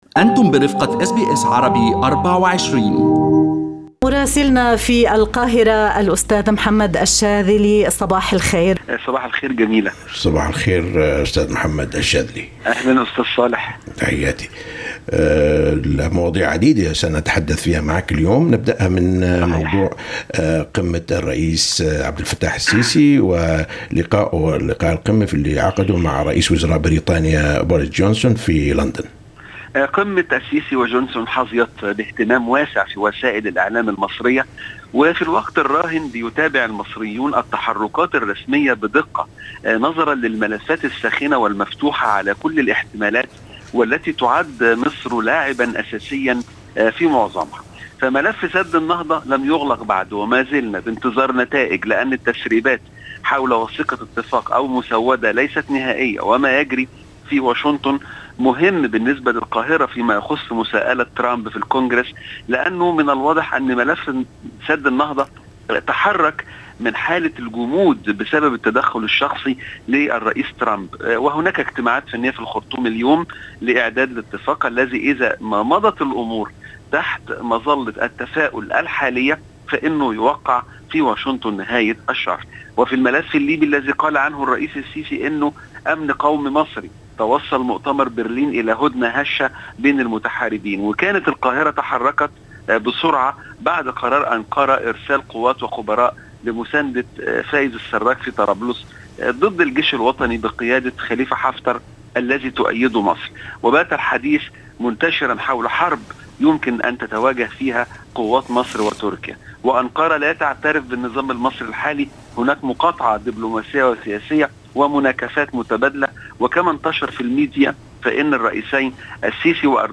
من مراسلينا: أخبار مصر في أسبوع 22/01/2020